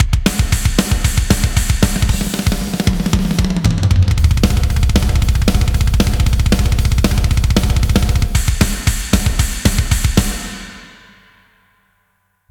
экстремальный металл )
Ну если это сухие барабаны , то я пожалуй балерина )) а на другие инструменты в этом жанре - ревер не предусмотрен ) кроме соло гитар ) вокала и синтов) там есть , и прилично)( Вложения drums.mp3 drums.mp3 491,9 KB · Просмотры: 825